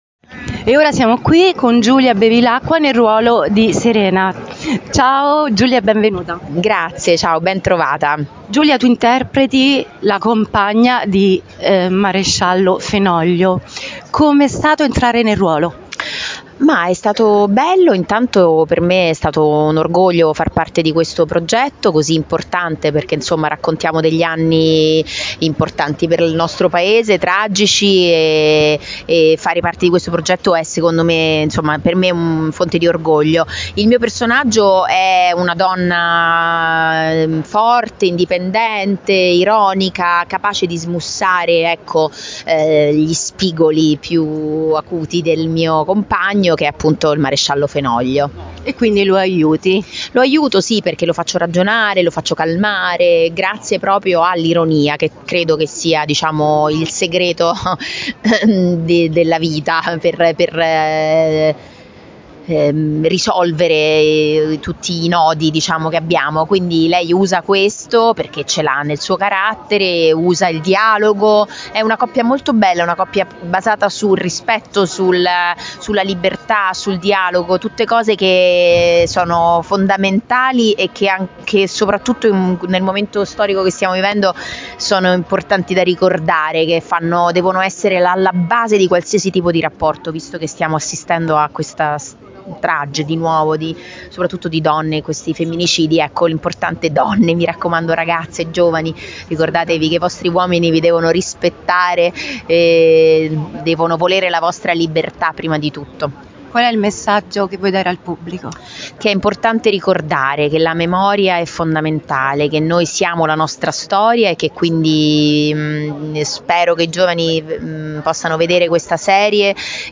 Giulia Bevilacqua       intervista